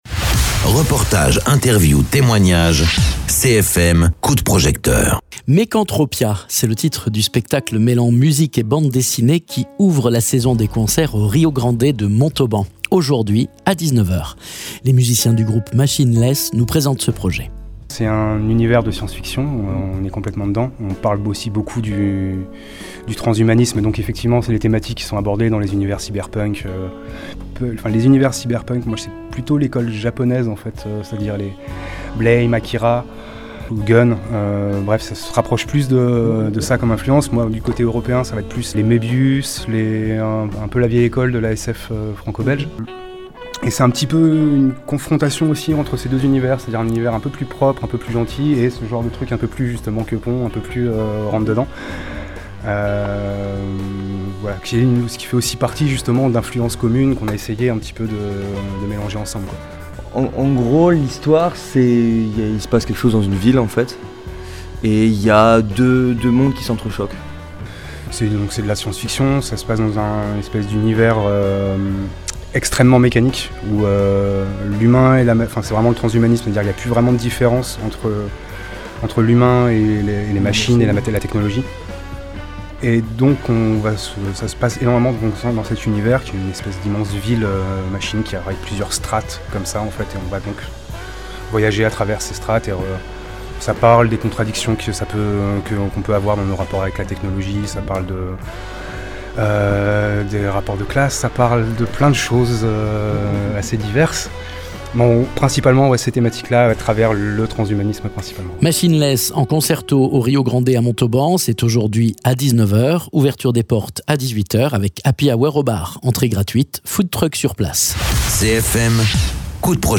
Interviews
Les musiciens du groupe Machinelesse nous présentent ce projet.